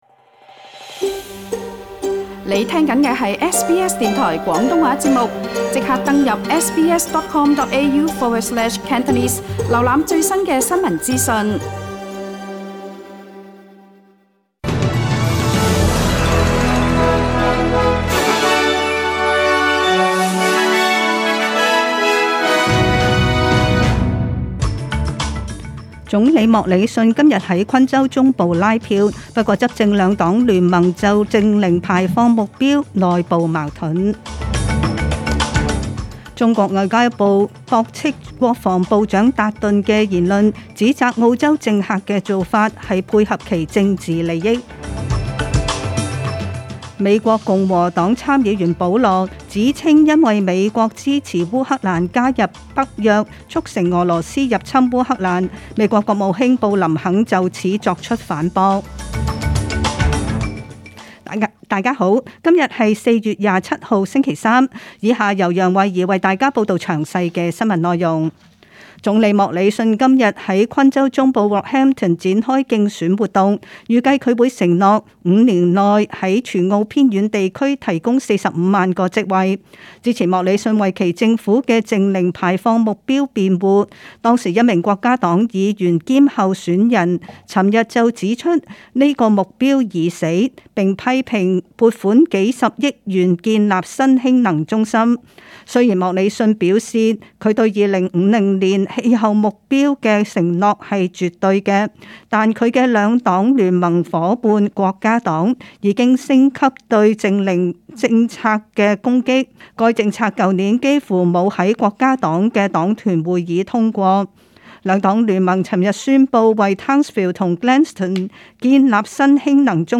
SBS 中文新聞（4月27日）